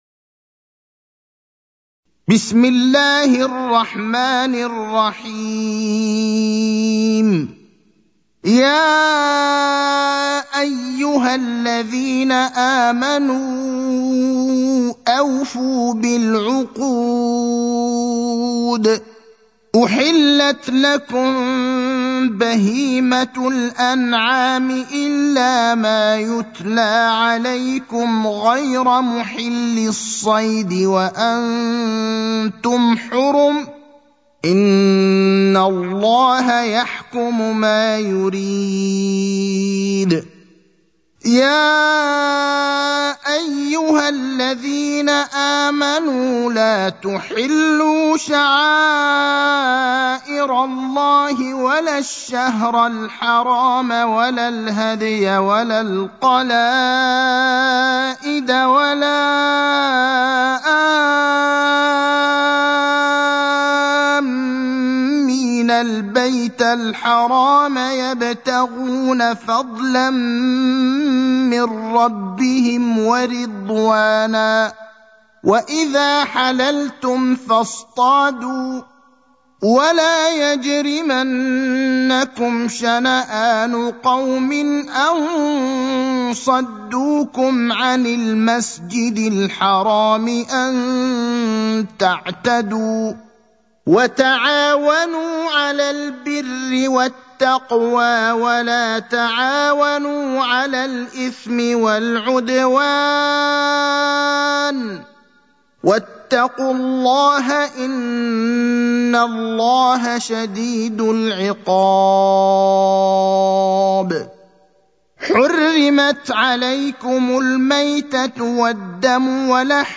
المكان: المسجد النبوي الشيخ: فضيلة الشيخ إبراهيم الأخضر فضيلة الشيخ إبراهيم الأخضر المائدة (5)